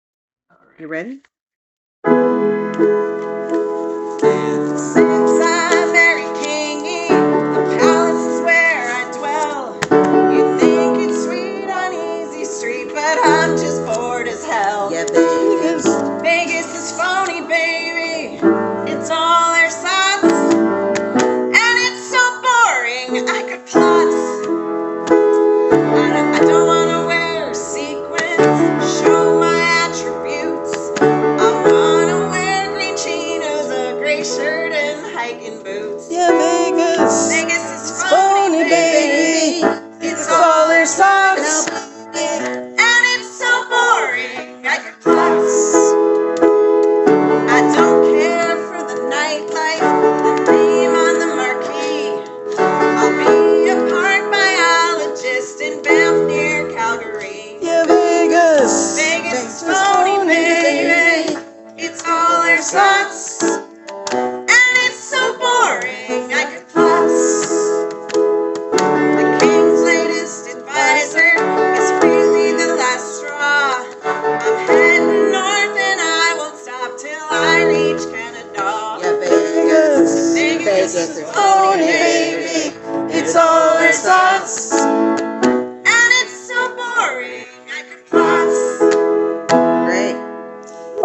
Rough recordings.
Tempos on some things could get faster when we are more familiar.